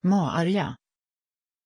Aussprache von Maarja
pronunciation-maarja-sv.mp3